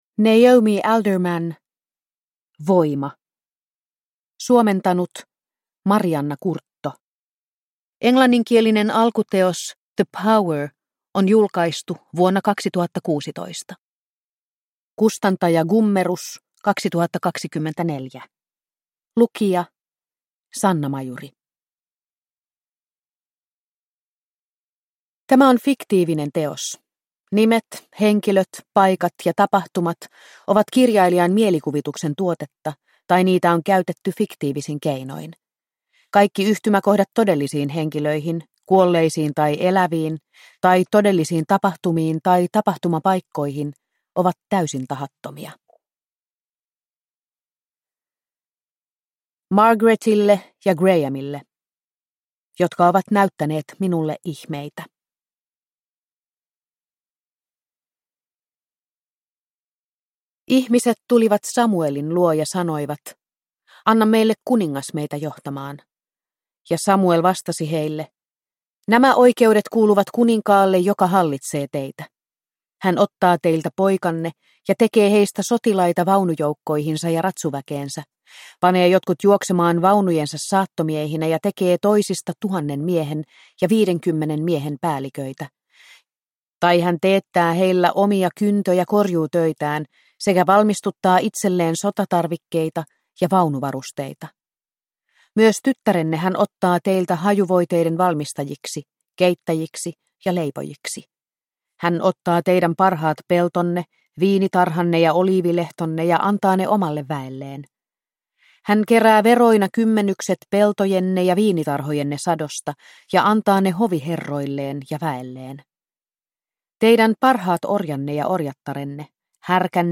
Voima – Ljudbok